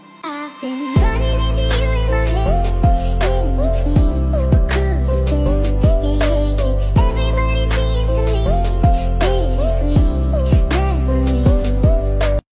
Chin Sound Effects MP3 Download Free - Quick Sounds